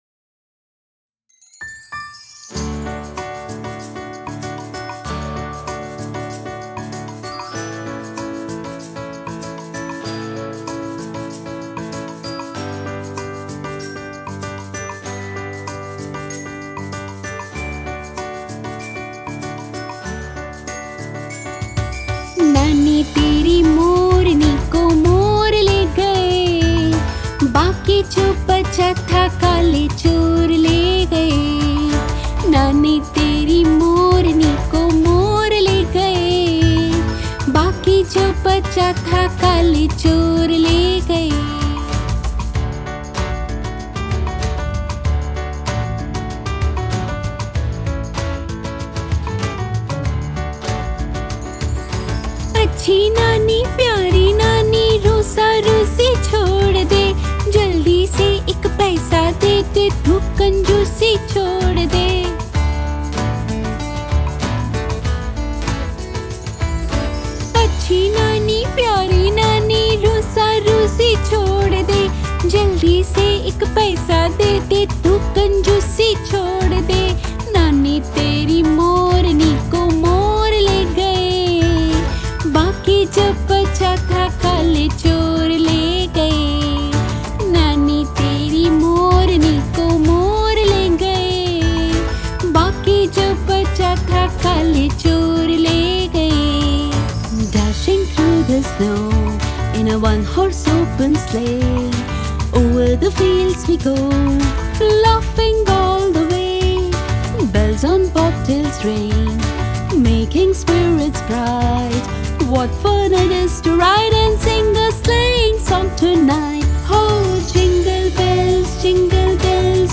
Female
My voice is pleasant , soft and compassionate.
Singing
Mashup Rhymes Hindi English
1121Nursery_Rhymes_mashup.mp3